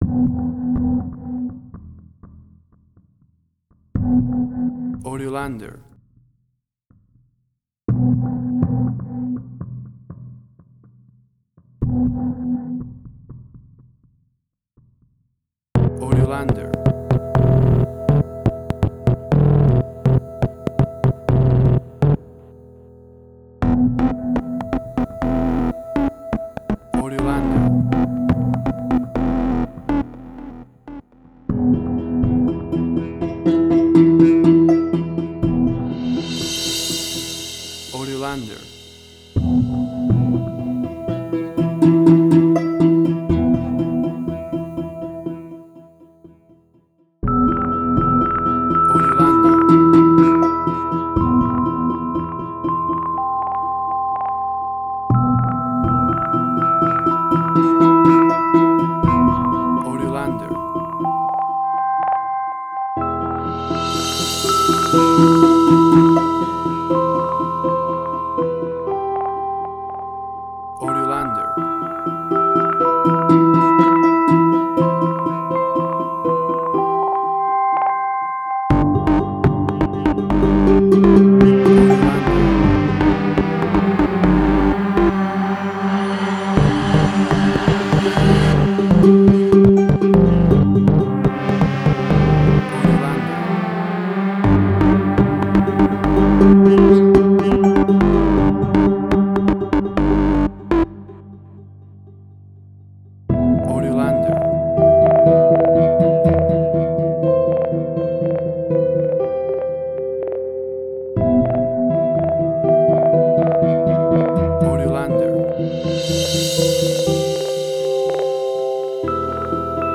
Suspense, Drama, Quirky, Emotional.
Tempo (BPM): 122